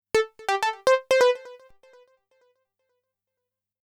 XTRA021_VOCAL_125_A_SC3.wav